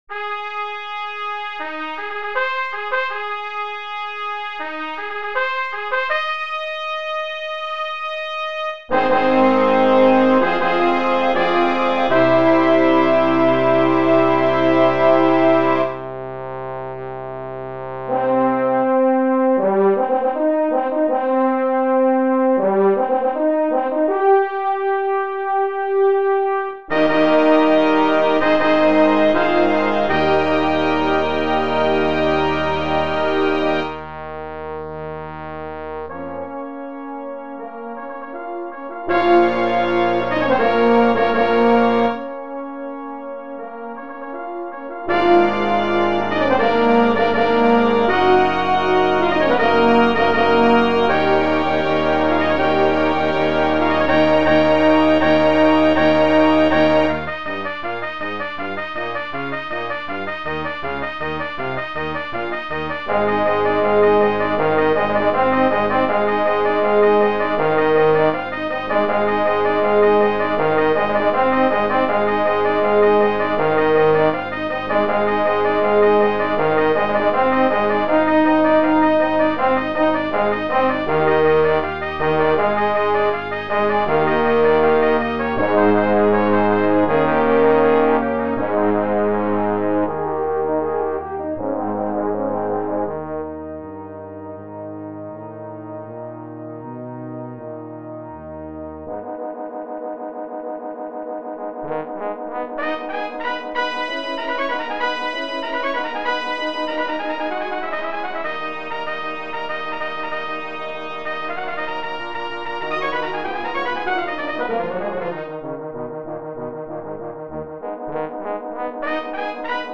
2. Brass Ensemble
10 brass players
without solo instrument
Classical, Classical Overture